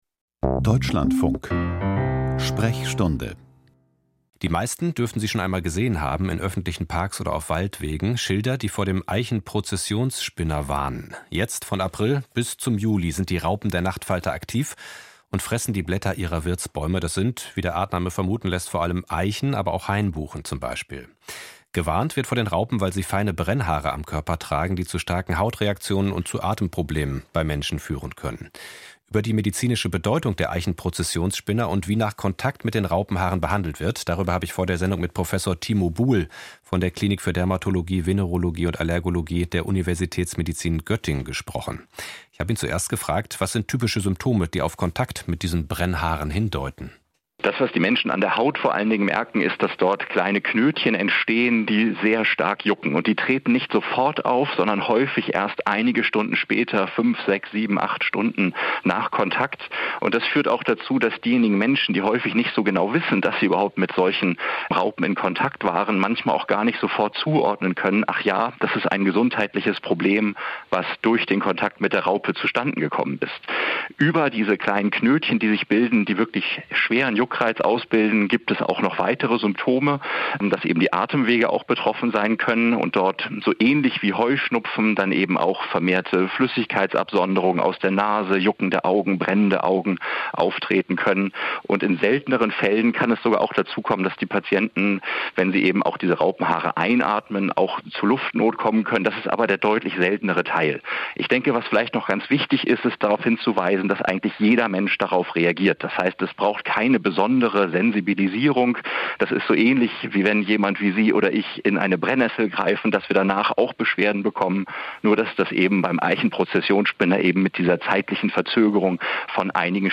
Eichenprozessionsspinner - was die Brennhaare auslösen: Interview